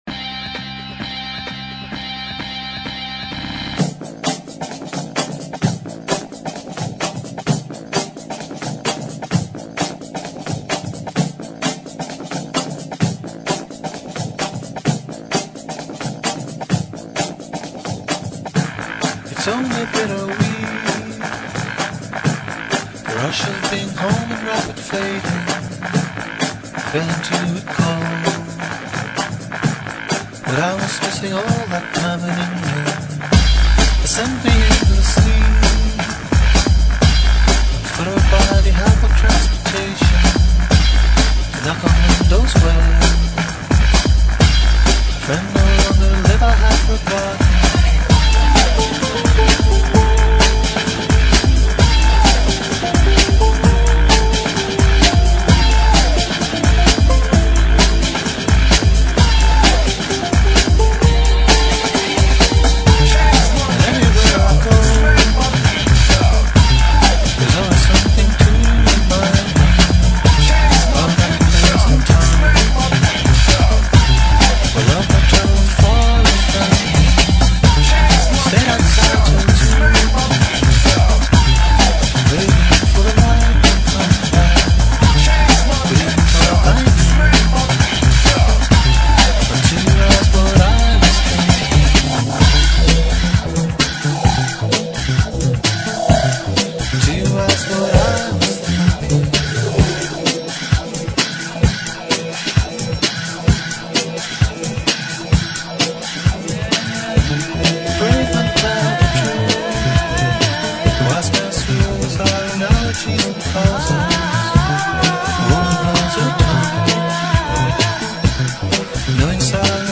also contains 5-to-the-floor bonus beats